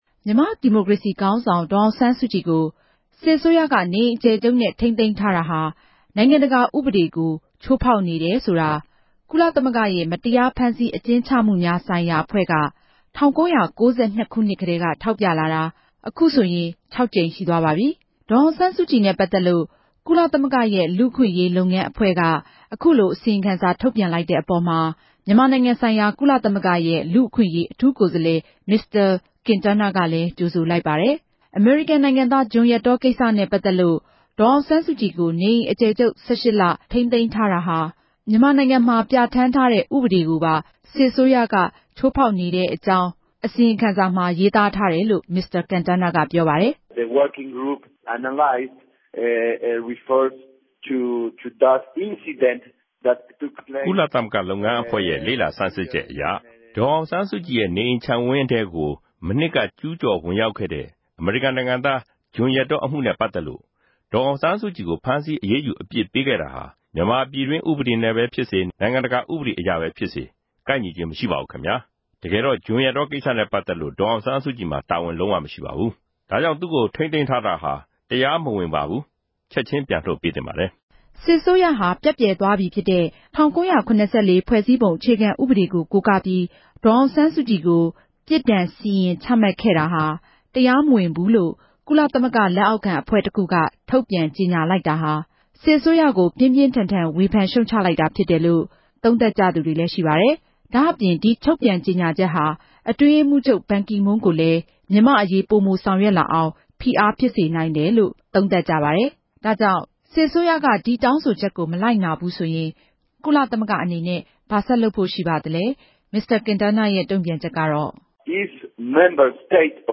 မင်္စတာ ကင်တားနားိံြင့် ဆက်သြယ်မေးူမန်းခဵက်။